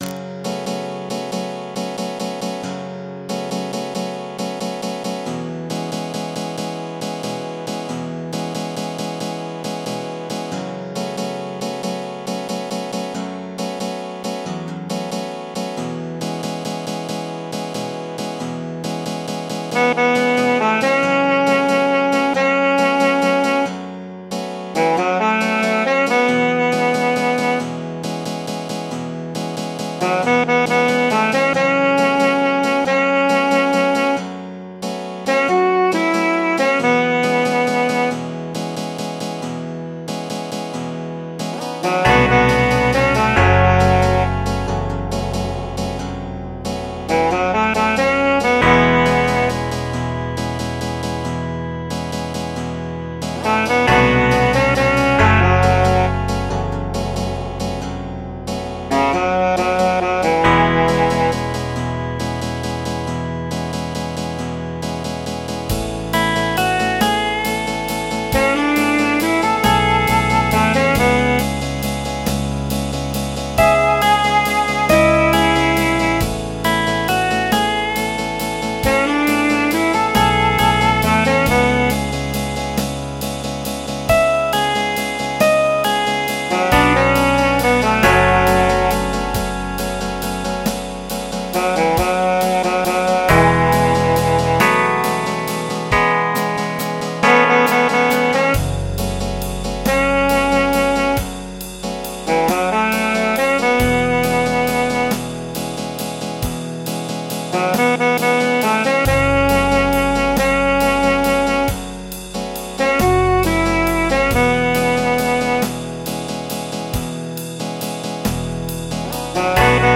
MIDI 49.34 KB MP3
This version actually has the bass and drums